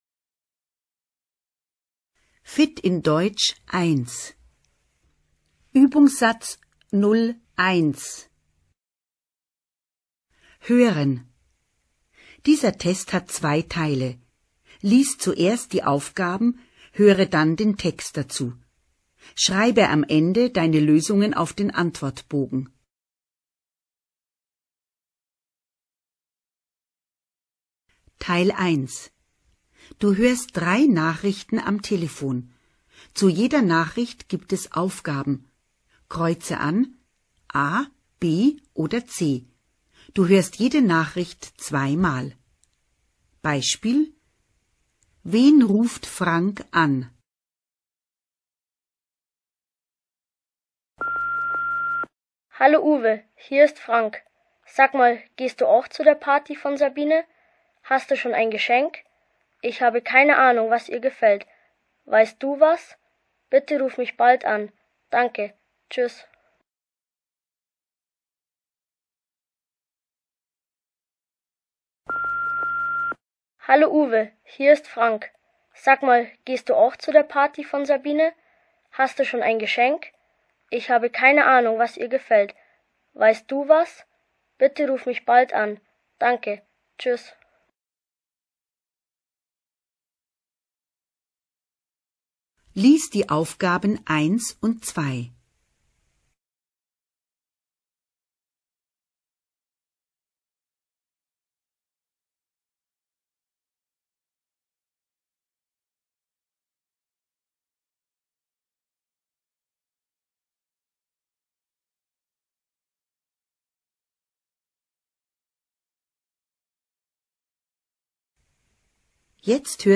Fit in Deutsch 1 Listening Test 1.mp3